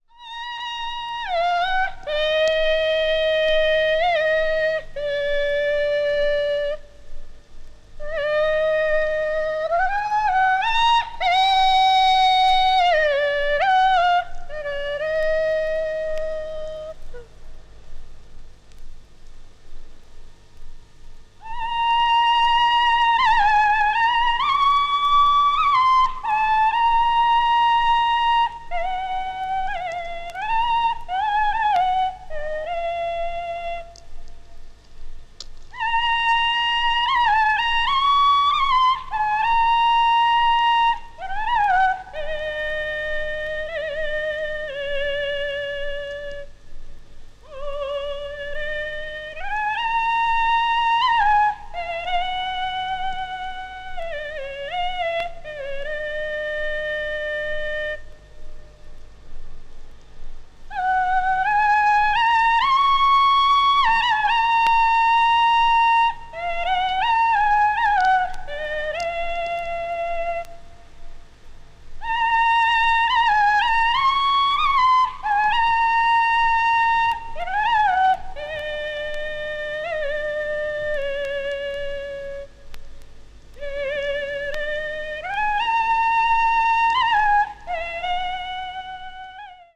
Ancient Swedish Pastoral Music
高音域のファルセット・ボイスを楽器的な方法で発音し、好条件であればその呼び声は約5km先まで聞こえるそうです。